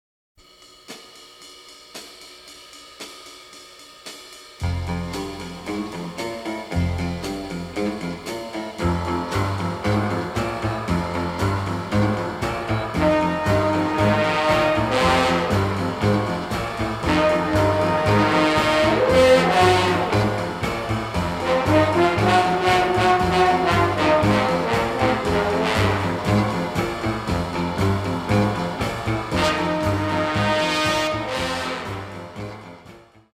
main theme which may jog their memory.